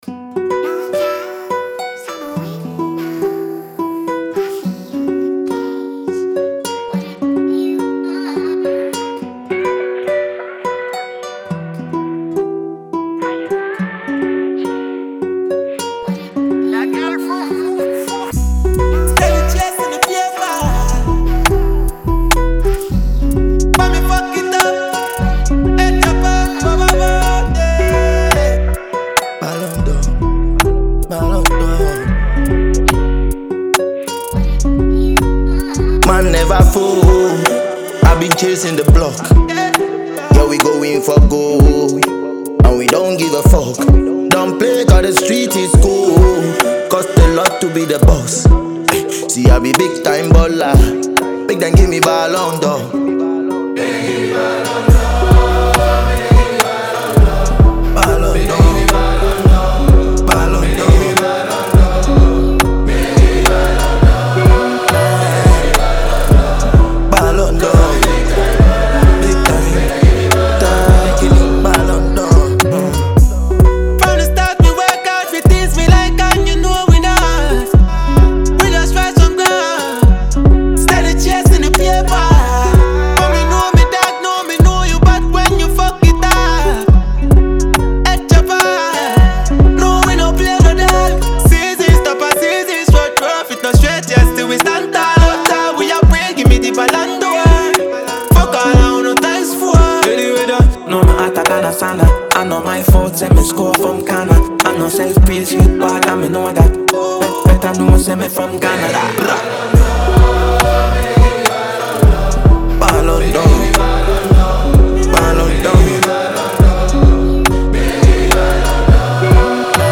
a confident and celebratory Afrobeat/Afropop record
delivers bold and self-assured verses
smooth and melodic vocals
• Genre: Afrobeat / Afropop